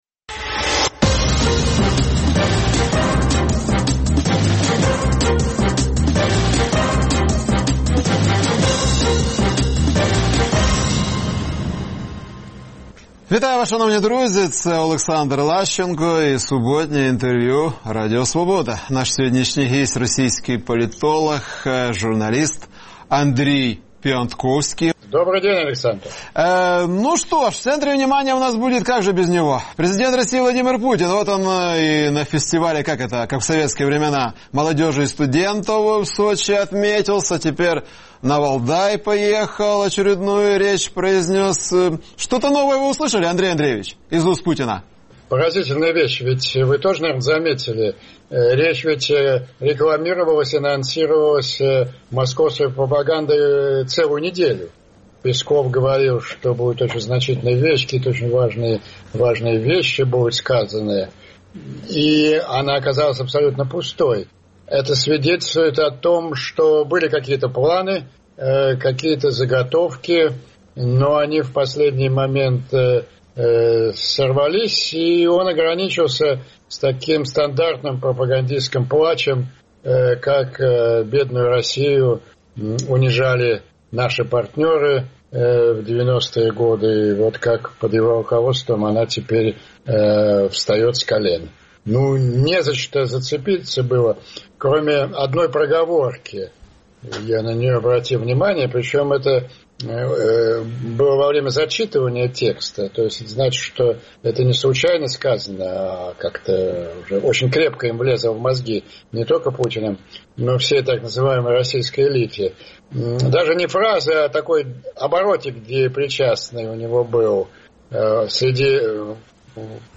Суботнє інтвер’ю - розмова про актуальні проблеми тижня.